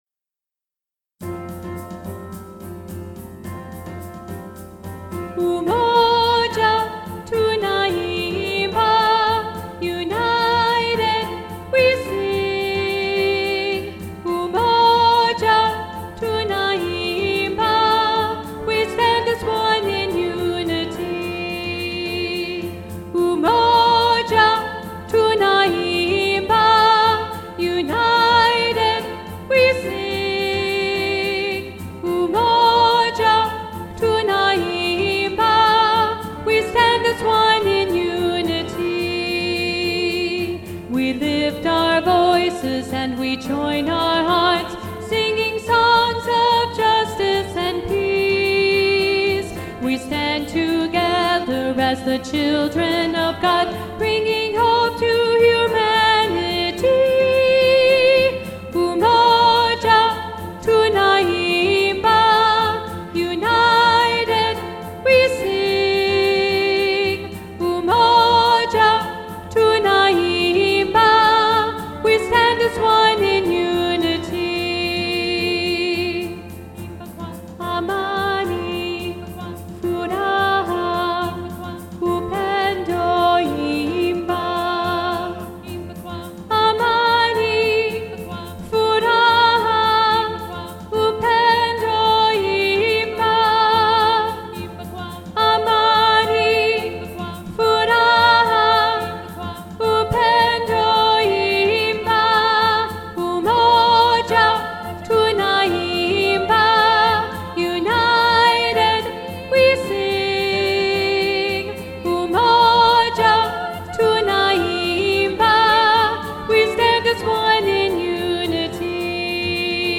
3-Part Mixed – Part 1b Predominant